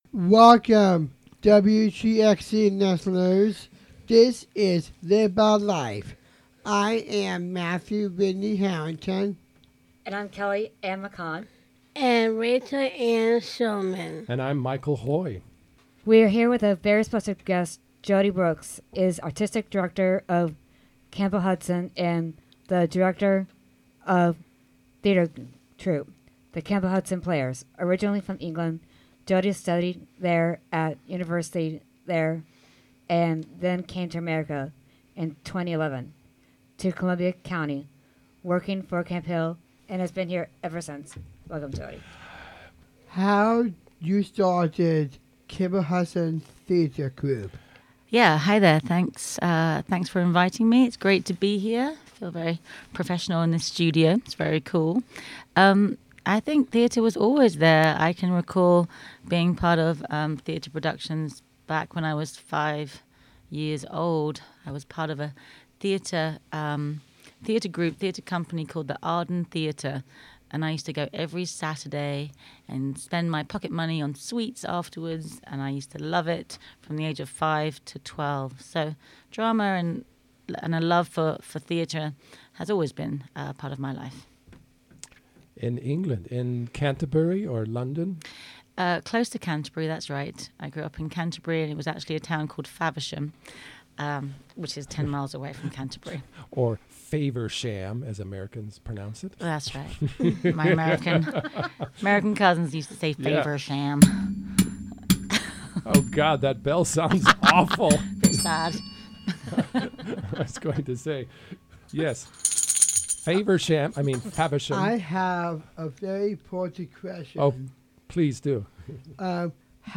Local interviews from the Camphill Media Group.